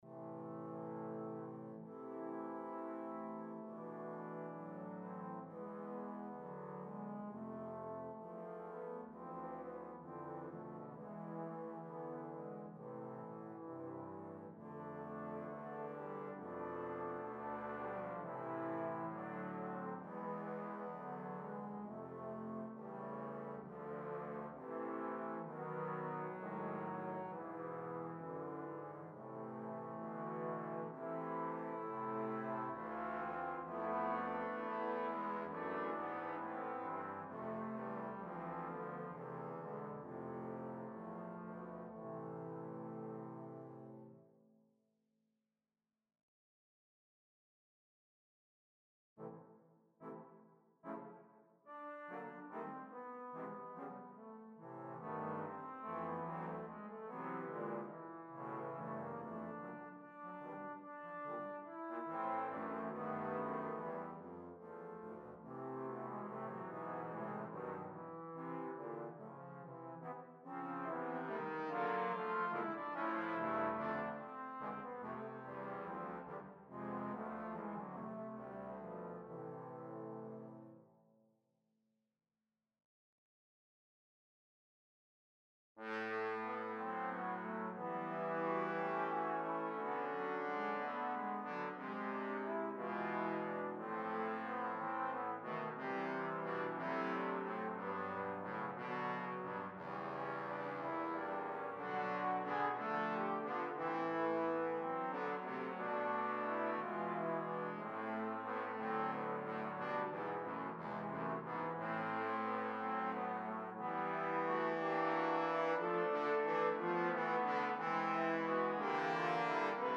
für vier Posaunen
Besetzung: Trombone Quartet